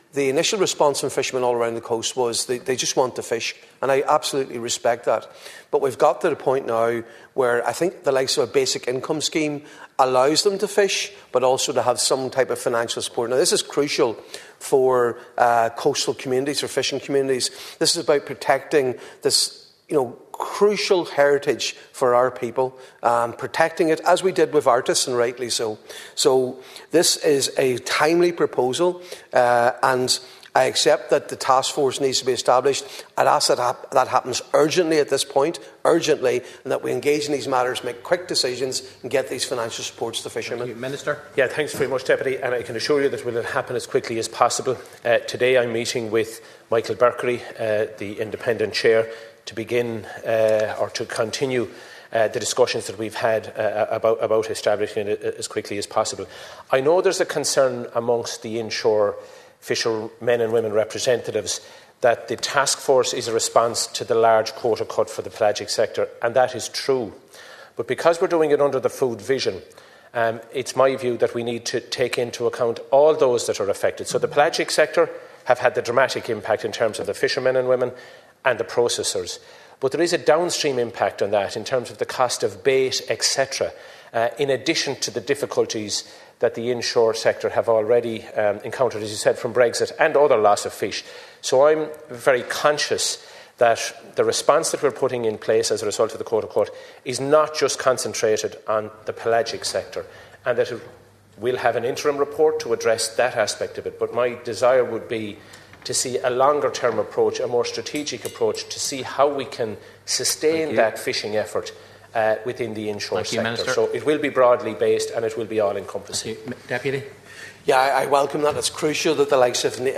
That’s according to Fisheries Minister Timmy Dooley, who was responding in the Dáil to an appeal from Donegal Deputy Pádraig MacLochlainn.
Minister Dooley says his aim is to develop an Income Stabilisation and Climate Compensation Scheme that would extend beyond the pelagic sector: